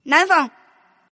Index of /client/common_mahjong_tianjin/mahjongjinghai/update/1124/res/sfx/tianjin/woman/